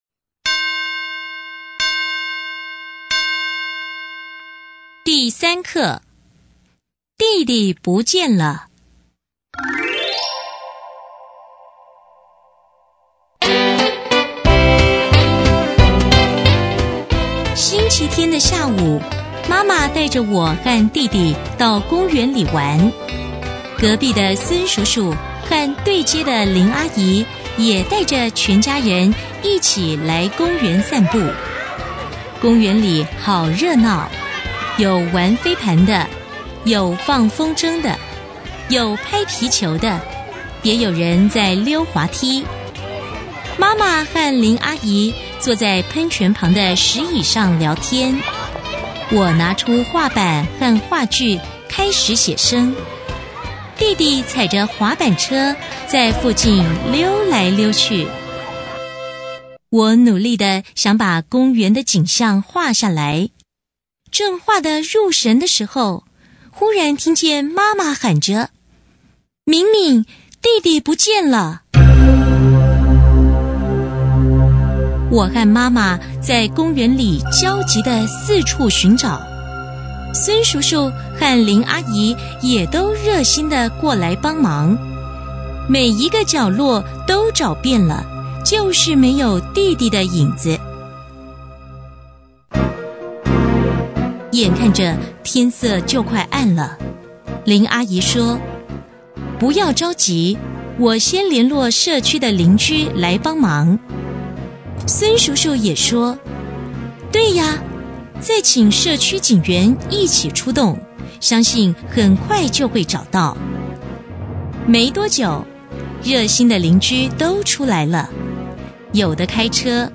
頂六個人版